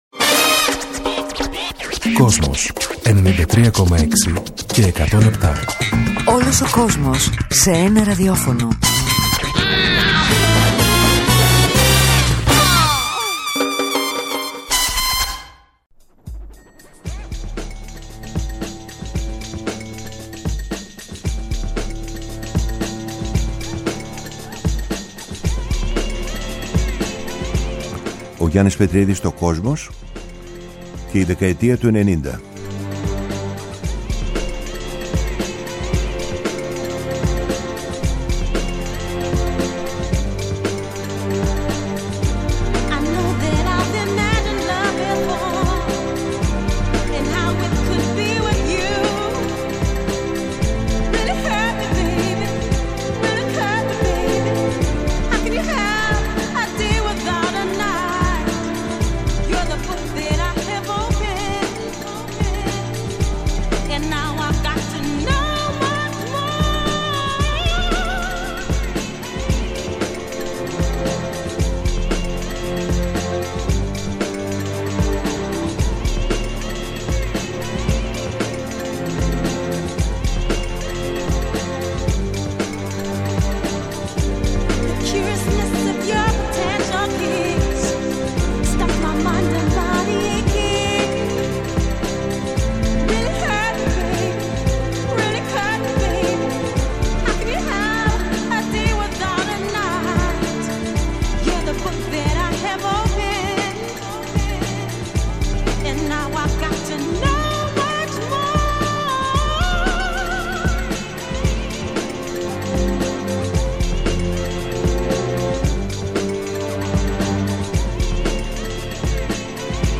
Ακολουθούν μία ανασκόπηση του grunge , του τελευταίου σπουδαίου μαζικού κινήματος του ροκ, καθώς και αφιερώματα στο post punk , το trip hop , τη house , την electronica , τη χορευτική μουσική και άλλα καθοριστικά μουσικά είδη της συγκεκριμένης 20ετίας.